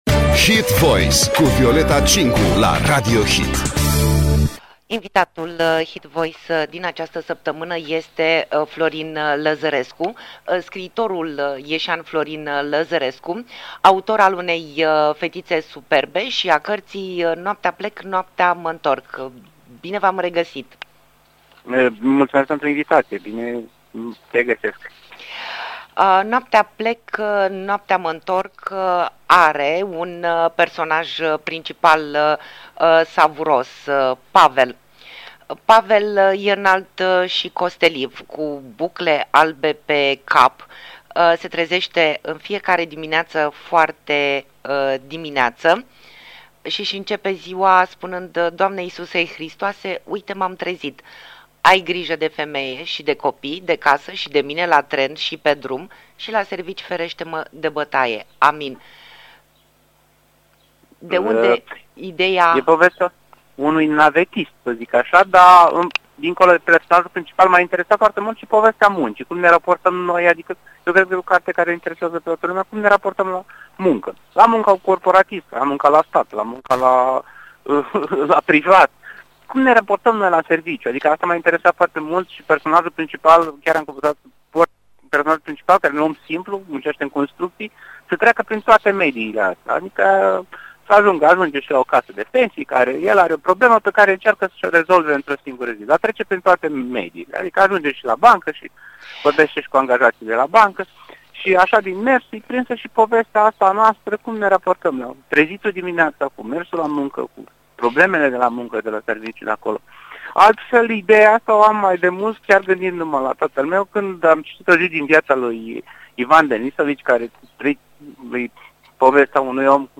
Interviu inedit cu scriitorul ieșean Florin Lăzărescu. O carte despre tații noștri și lumea de astăzi